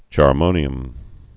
(chär-mōnē-əm)